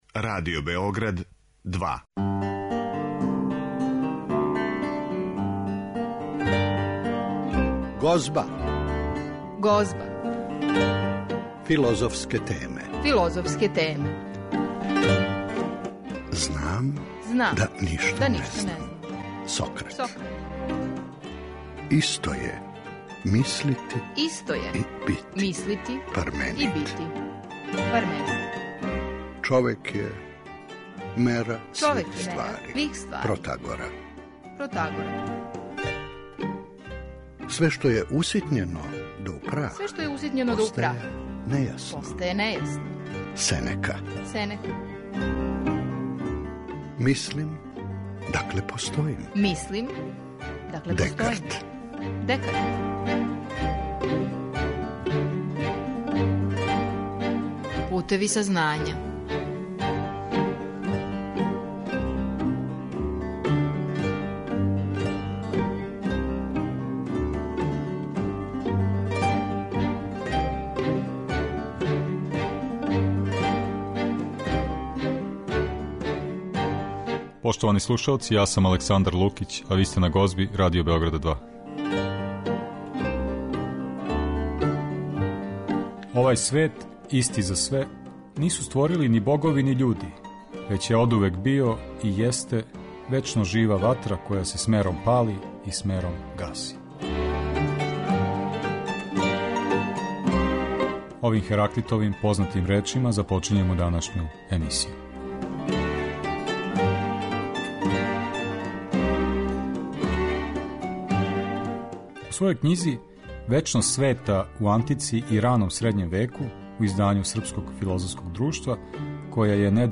O problemu večnosti sveta u emisiji Gozba razgovaramo